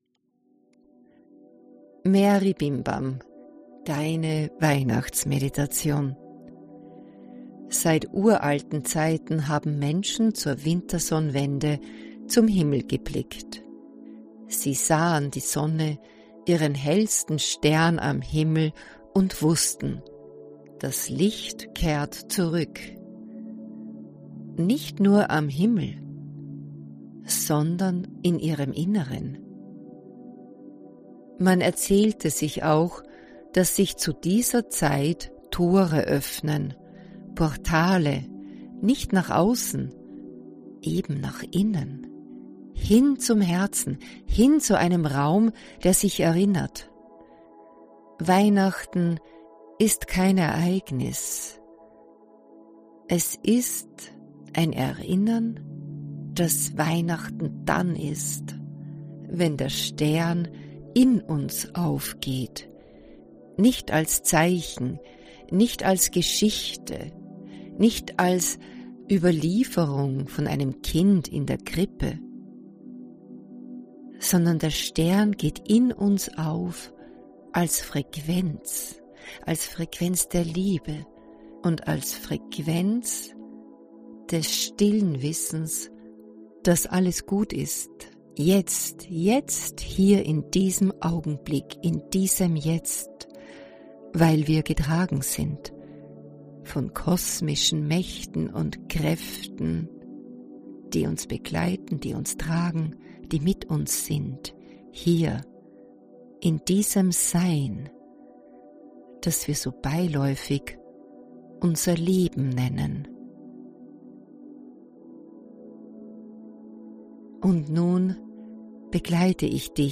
Mit dieser geführten Weihnachtsmeditation kommst Du nach Hause. Zu Dir. Zu Deinem Stern IN Dir!